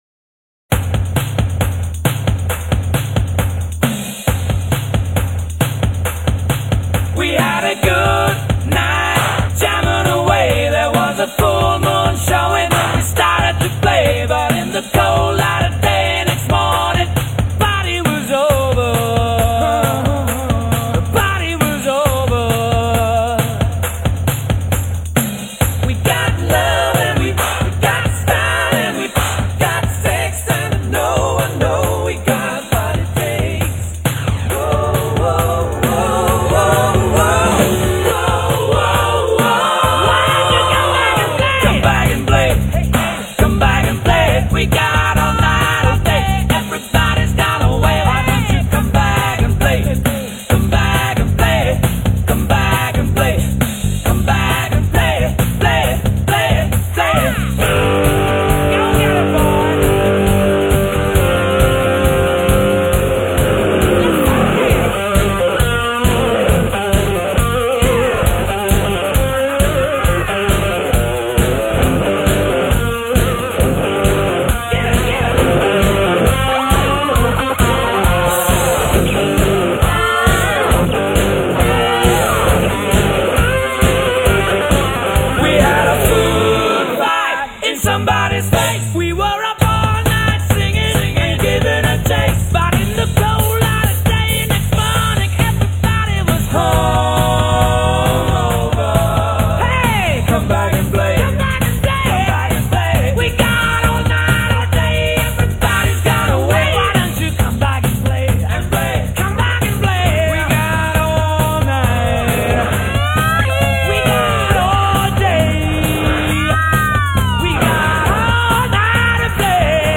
Rock, Pop Rock, Hard Rock